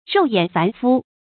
肉眼凡夫 注音： ㄖㄡˋ ㄧㄢˇ ㄈㄢˊ ㄈㄨ 讀音讀法： 意思解釋： 肉眼：佛經中說有，天、肉慧、法佛五眼，肉眼為肉身之眼，也泛指俗眼；凡夫：指凡人。